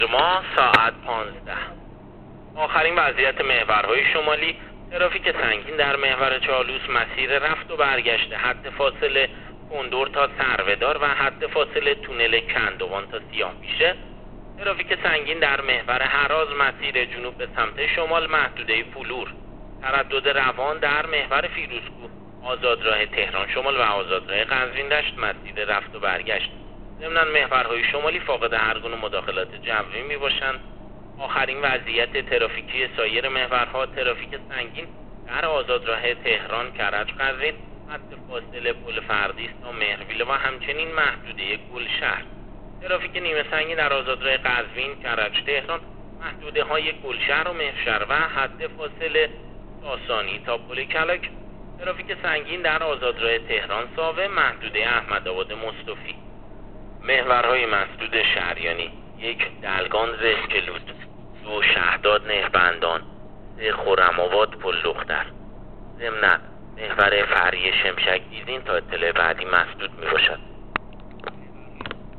گزارش رادیو اینترنتی از آخرین وضعیت ترافیکی جاده‌ها تا ساعت ۱۵ بیست‌وهشتم تیر